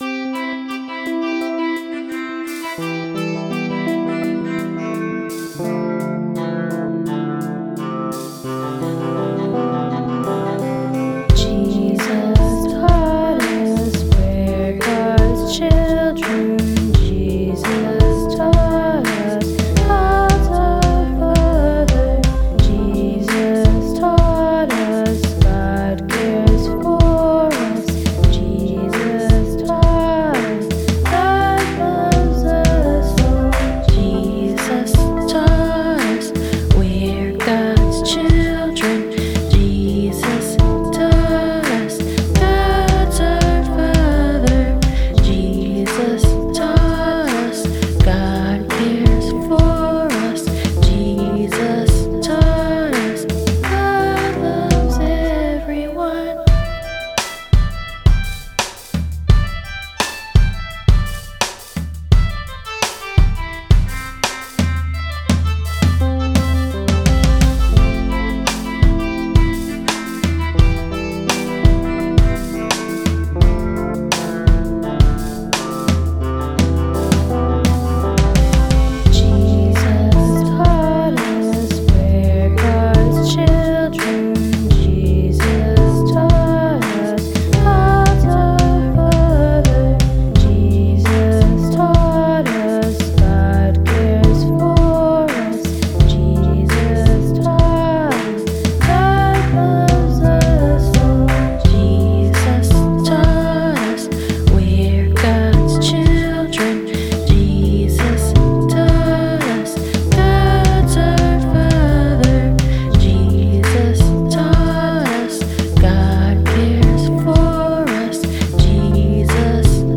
Chords: Fmaj7 Em Dmadd9 C